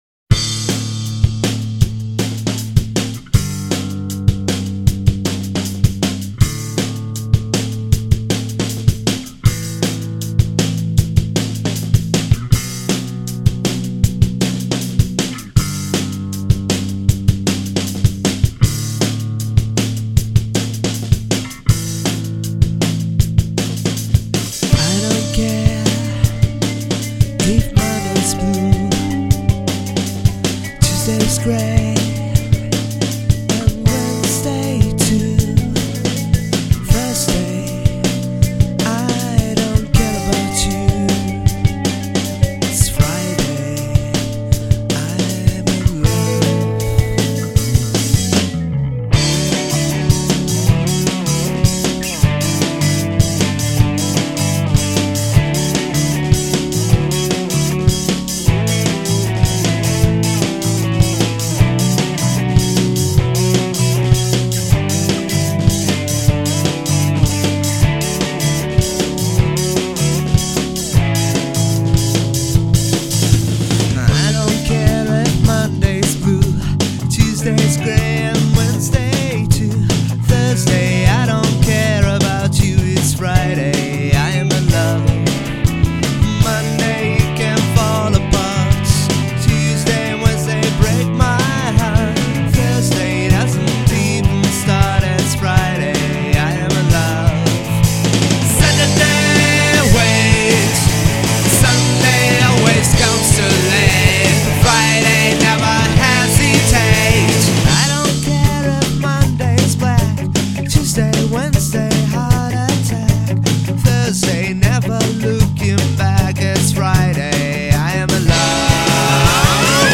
LIVE BONUS: